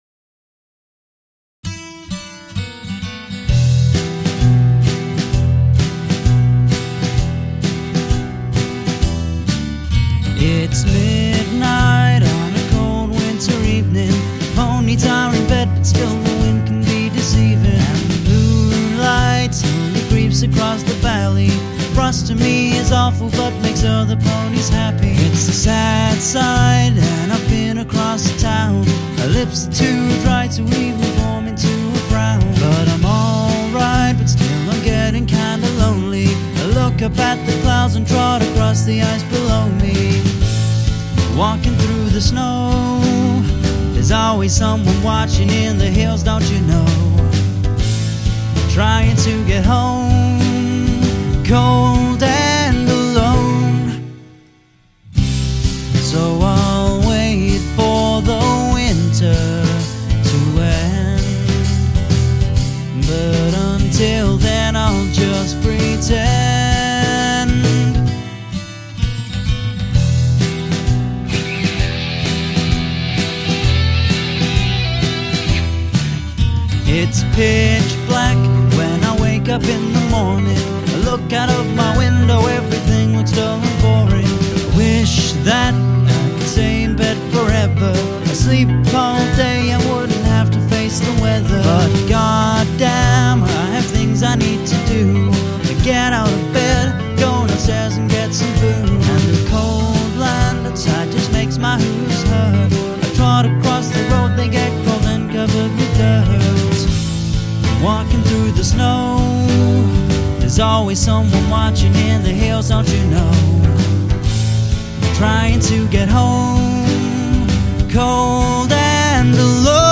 This song is in E minor where as Summer is in E major.